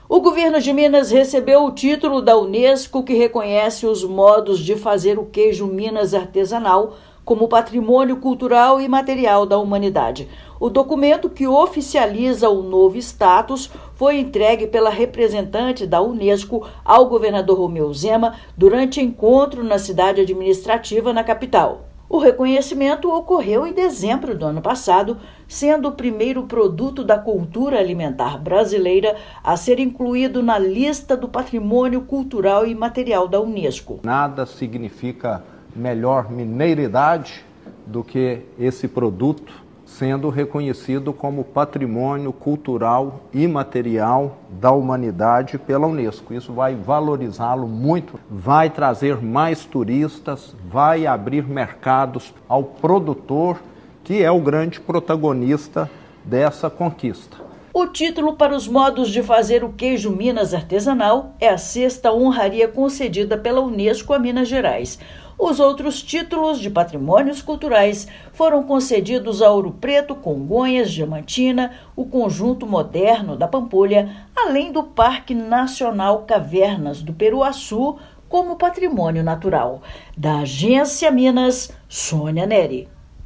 Este é o sexto bem cultural de Minas Gerais reconhecido pela Unesco e o primeiro produto alimentar brasileiro a fazer parte da Lista Representativa da instituição. Ouça matéria de rádio.